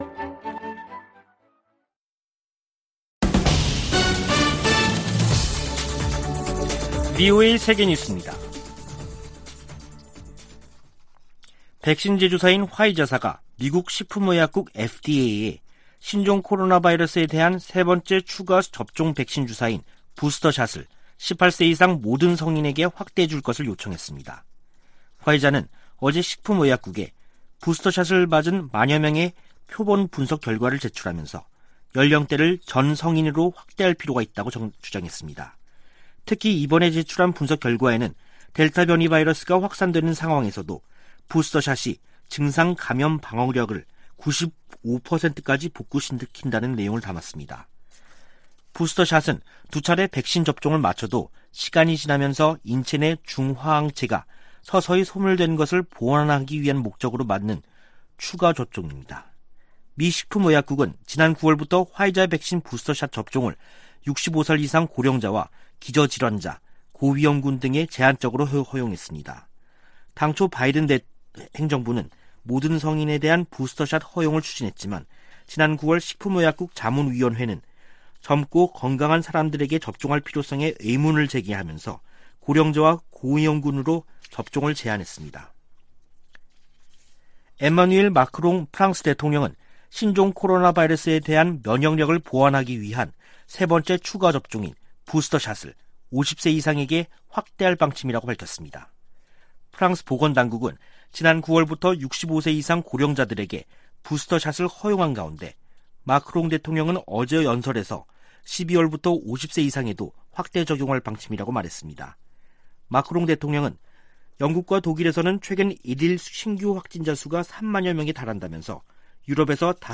VOA 한국어 간판 뉴스 프로그램 '뉴스 투데이', 2021년 11월 10일 3부 방송입니다. 미 국방부가 북한 문제에 중국의 역할을 다시 강조하며 유엔 안보리 제재 실행을 촉구했습니다. 미 의회의 올해 공식 의정활동이 다음달 중순 종료되는 가운데, 총 11건의 한반도 법안과 결의안이 상·하원에 계류 중입니다. 북한 당국이 김정은 국무위원장 집권 기간 시장 활동을 제도화하면서 당-국가의 정치적 통제를 강화하는 정치·경제 모델을 추구해왔다는 분석이 나왔습니다.